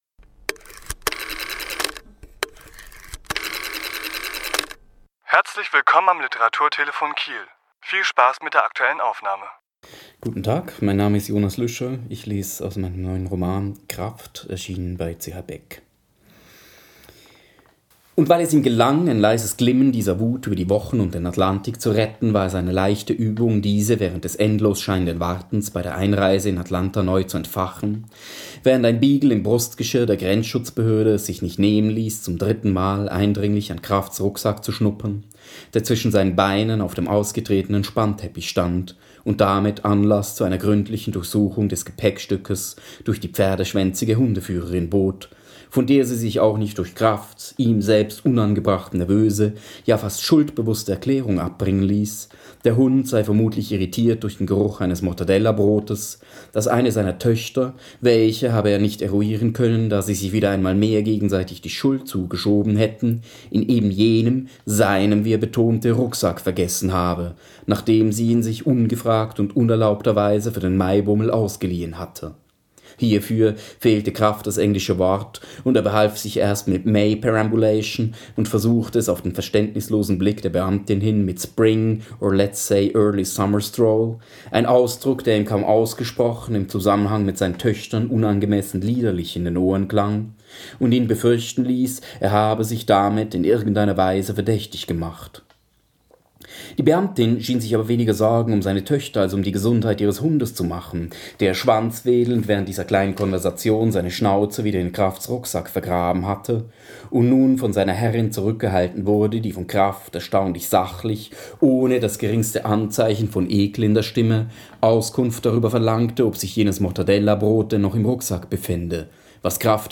Autor*innen lesen aus ihren Werken
Die Aufnahme entstand bei einer Lesung im Literaturhaus Schleswig-Holstein am 16.3.2017.